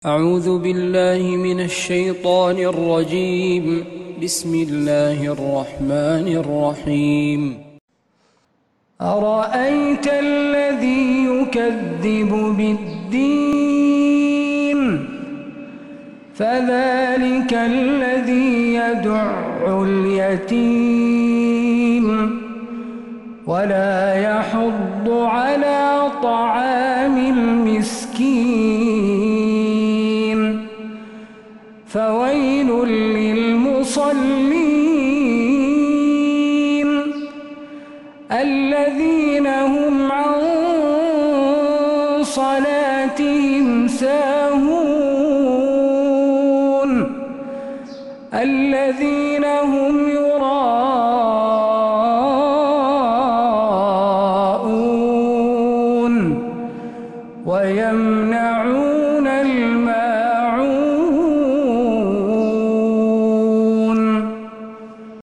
من مغربيات الحرم النبوي